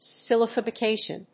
音節syl・lab・i・fi・ca・tion 発音記号・読み方
/sɪl`æbəfɪkéɪʃən(米国英語)/